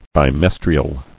[bi·mes·tri·al]